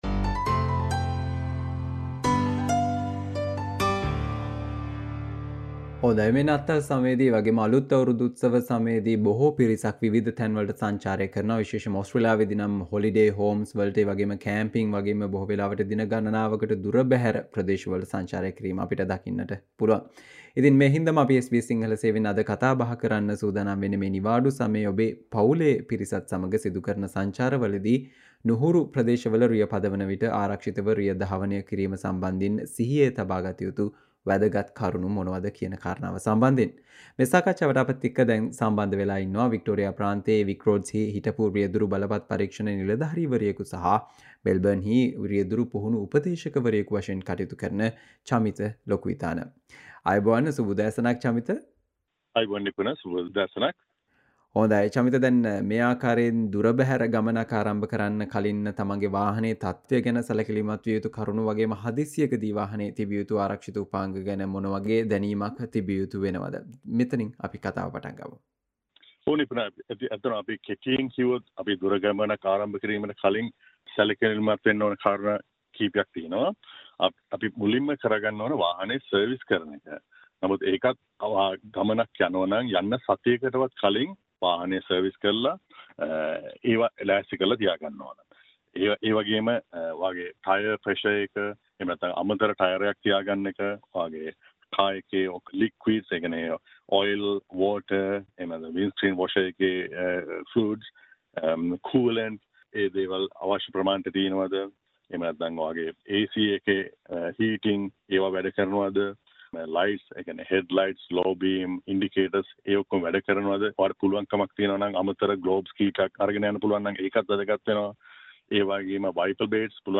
SBS Sinhala discussion on some tips to keep in mind to stay safe, when driving in unfamiliar territory this festive holiday